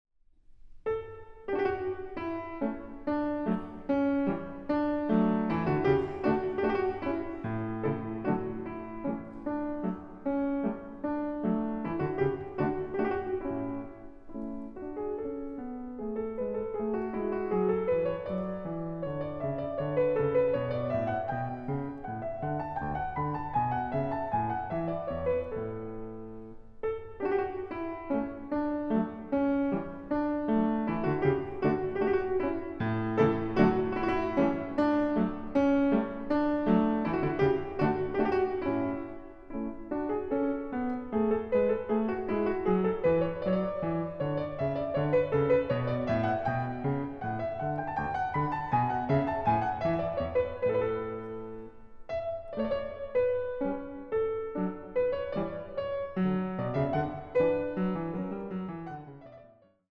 Piano
Recording: Großer Saal, Gewandhaus Leipzig, 2025